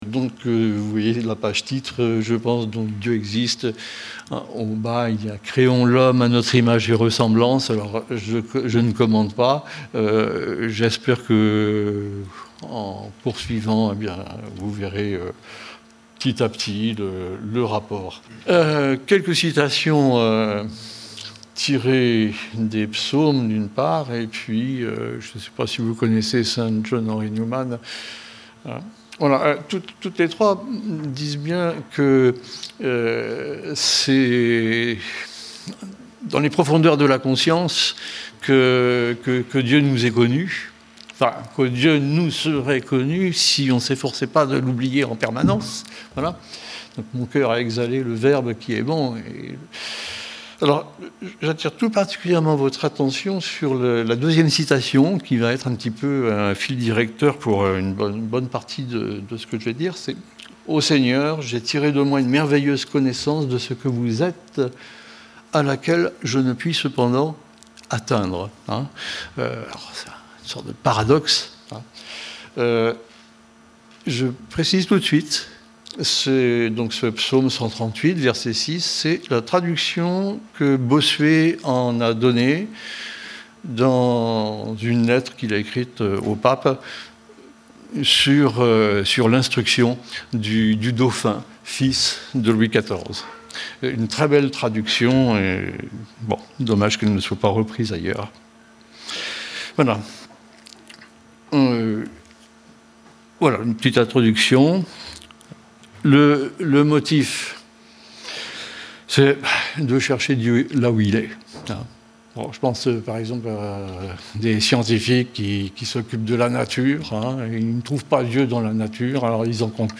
Enregistrement Audio de la conférence